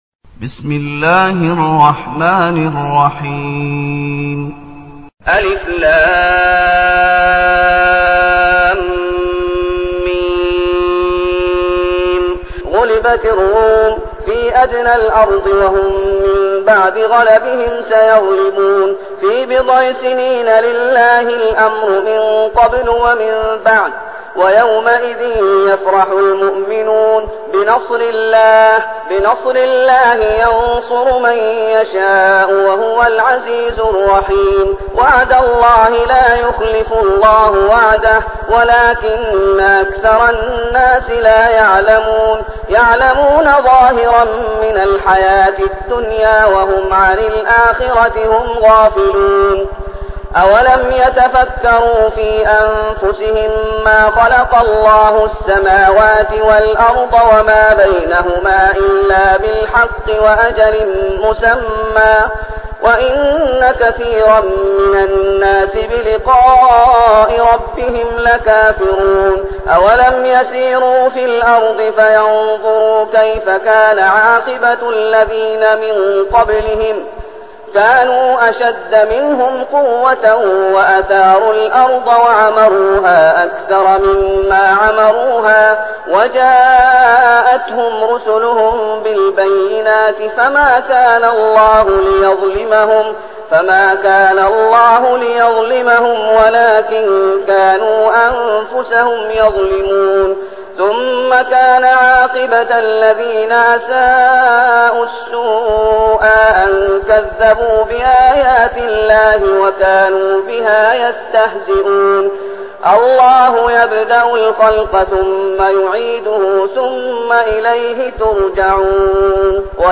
المكان: المسجد النبوي الشيخ: فضيلة الشيخ محمد أيوب فضيلة الشيخ محمد أيوب الروم